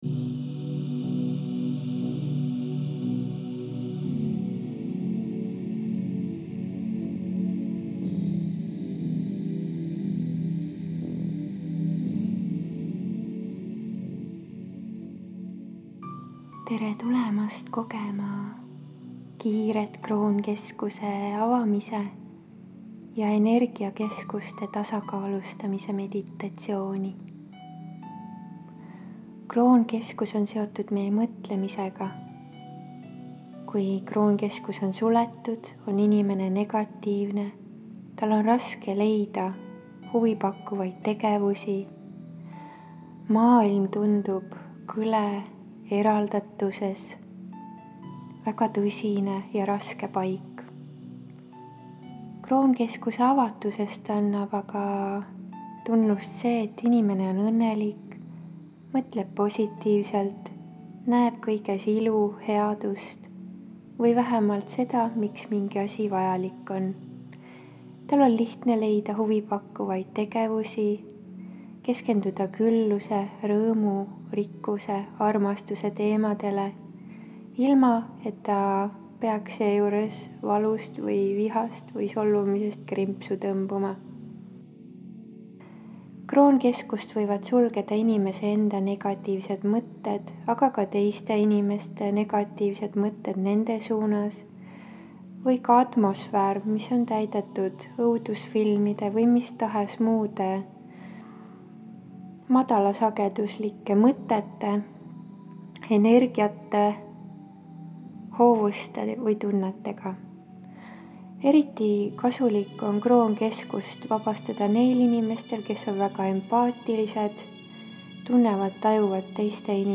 Siit lehelt saad kuulata ja alla laadida järgmist faili: KROONKESKUSE KIIRE AKTIVEERIMISE MEDITATSIOON Tule helgemasse eluvoogu!